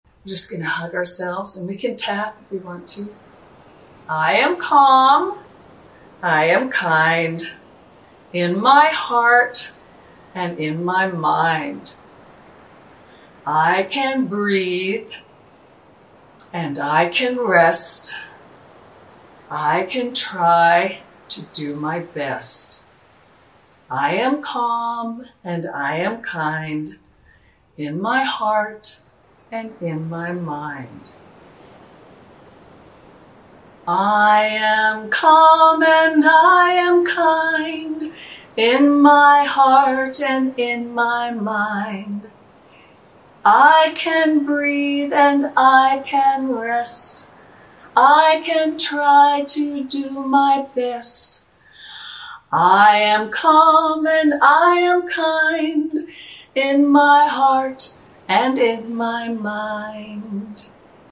easy to learn song